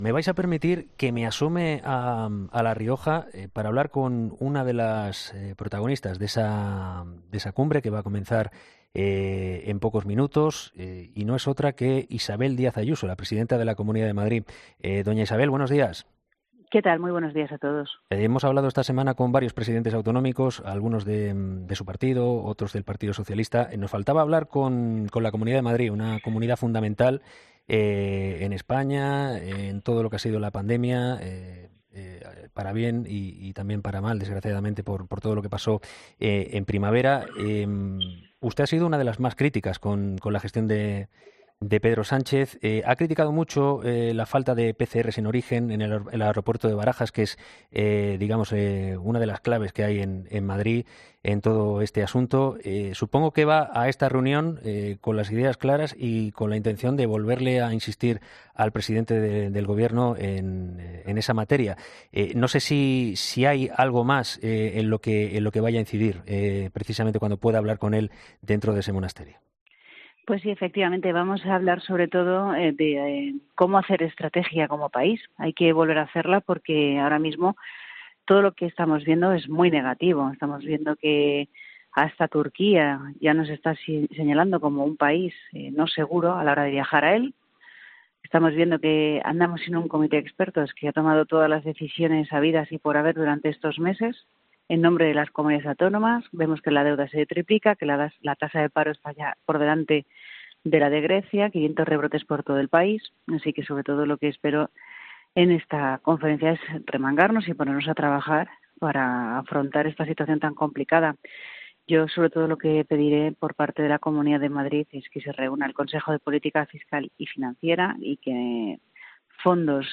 Isabel Díaz Ayuso, presidenta de la Comunidad de Madrid , ha sido entrevistada este viernes en 'Herrera en COPE' antes de que haya tenido comienzo la conferencia de presidentes autonómicos convocada por Pedro Sánchez para hacer frente a la crisis sanitaria y económica provocada por el nuevo coronavirus.